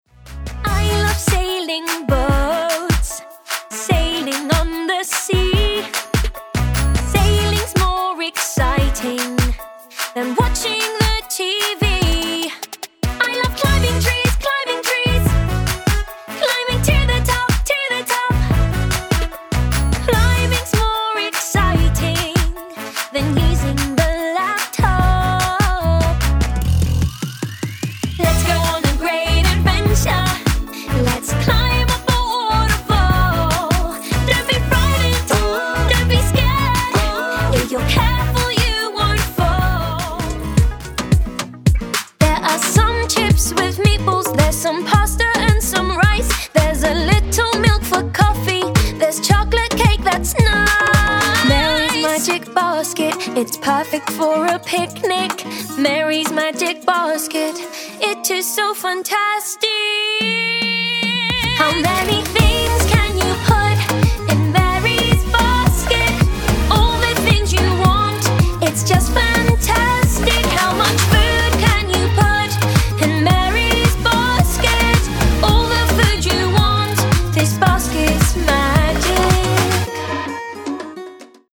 Kids Animation Singing Reel
Singing, Versatile, Kids Animation